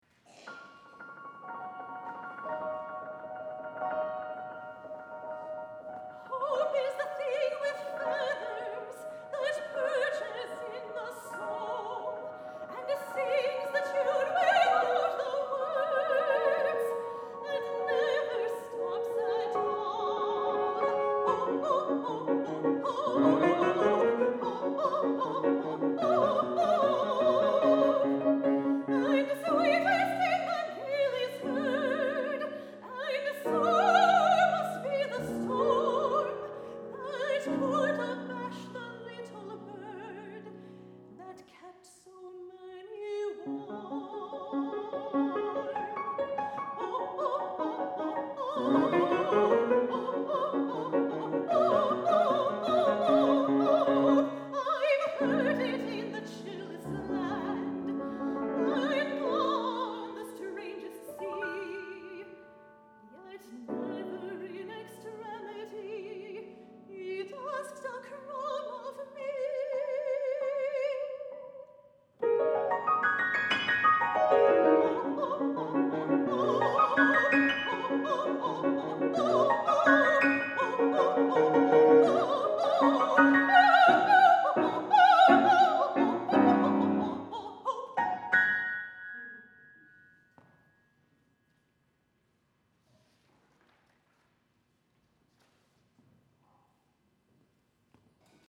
for Soprano and Piano (2014)